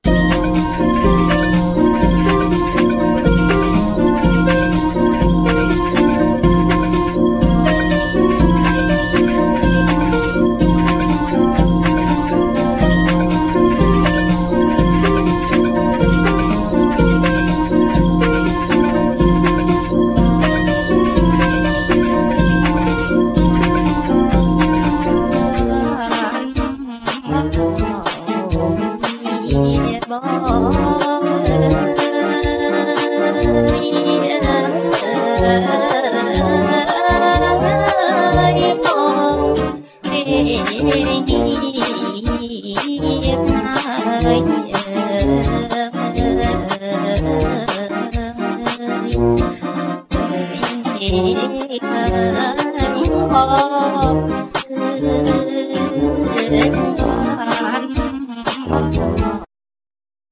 Drums,Bass,Keyboards,Vocal abuse
Guitar